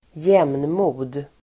Uttal: [²j'em:nmo:d]